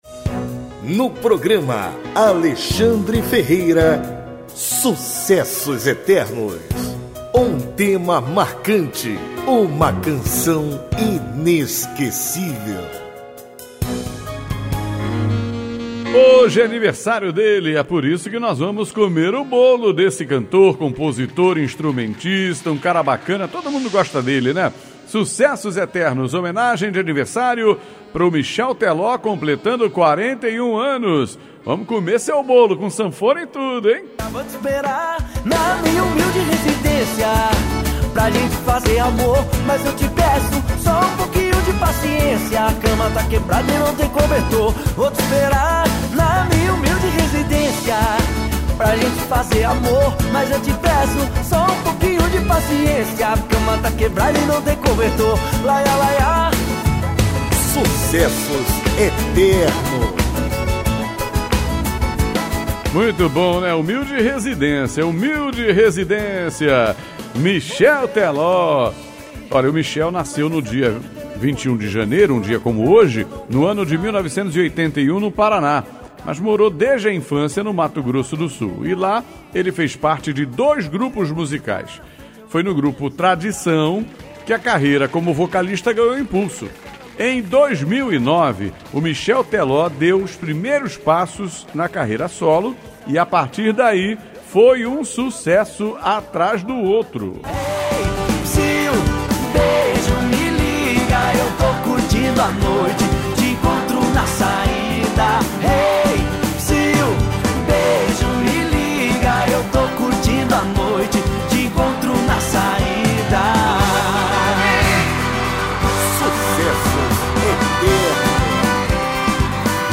O programa vai ao ar de segunda a sábado, a partir de meia-noite, com muita interação, bate-papo, informação e boa música.